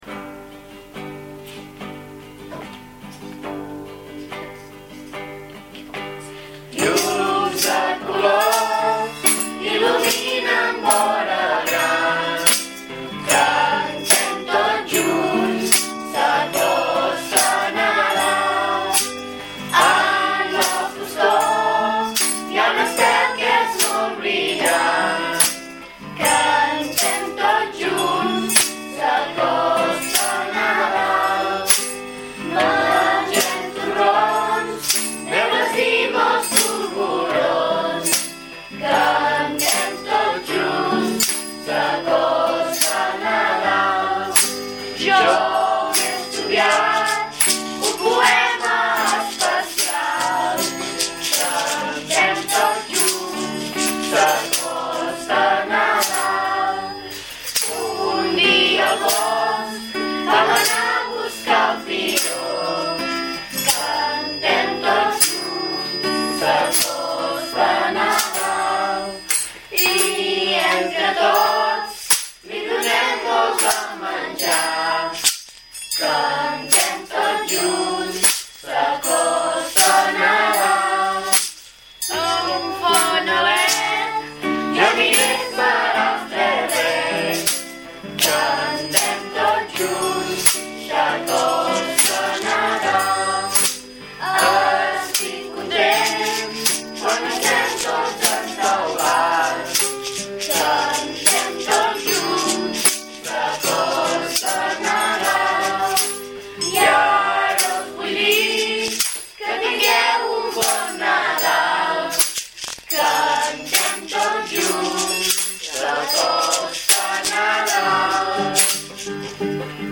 I acompanyem aquests desitjos amb música, amb la nostra nadala Llums de colors, composada per l’Alejandro García i la Rosa Cortada i interpretada pels alumnes de 6è.